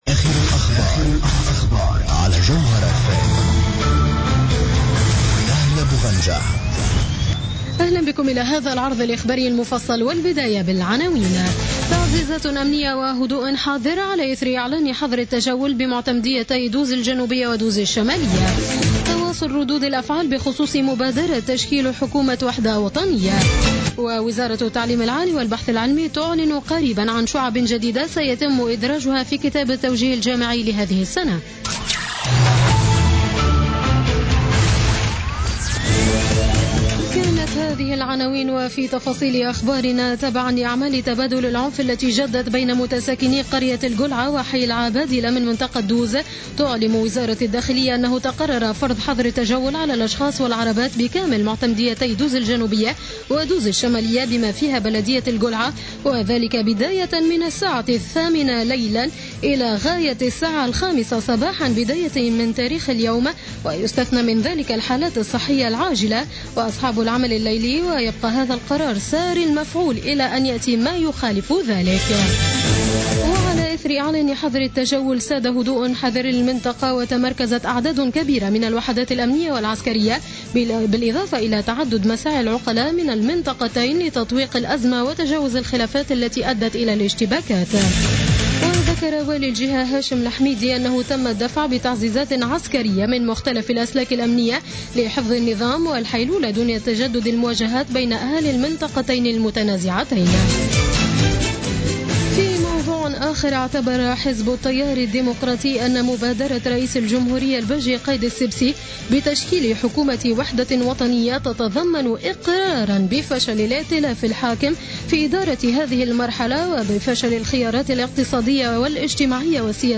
نشرة أخبار السابعة مساء ليوم السبت 4 جوان 2016